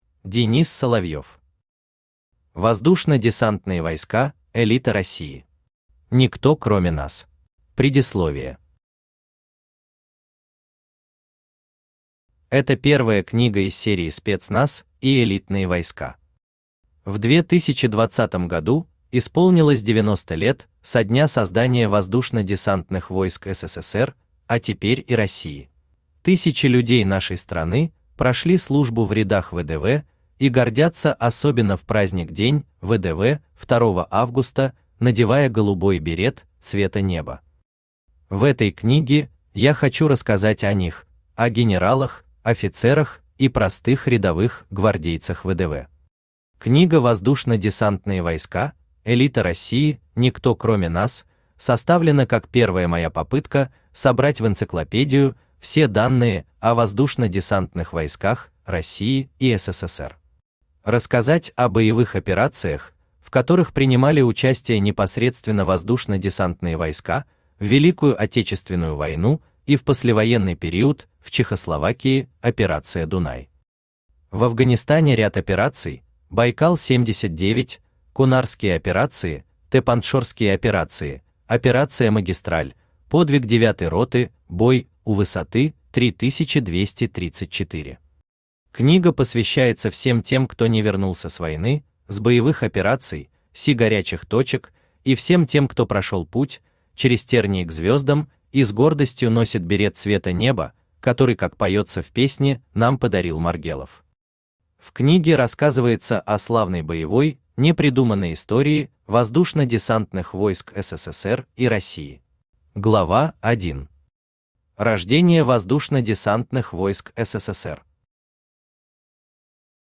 Аудиокнига Воздушно-десантные войска – элита России. Никто кроме нас | Библиотека аудиокниг
Никто кроме нас Автор Денис Соловьев Читает аудиокнигу Авточтец ЛитРес.